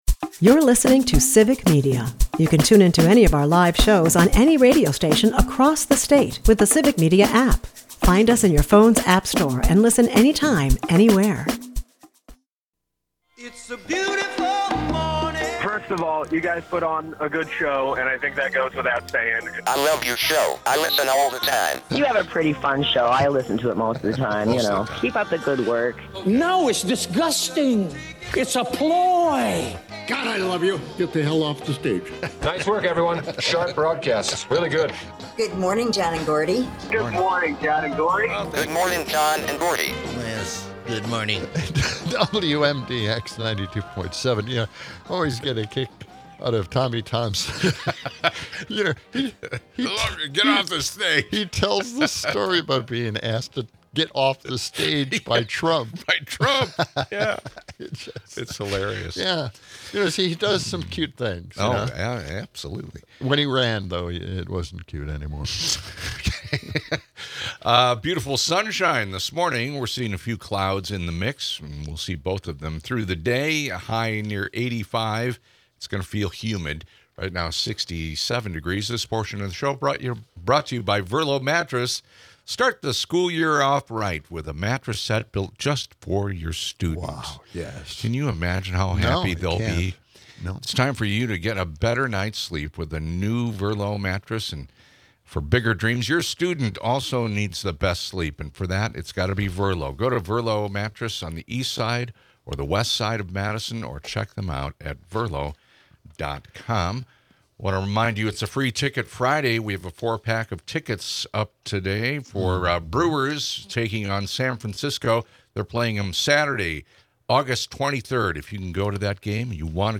Amidst lively banter, political tensions surface with a critique of authoritarian practices in DC.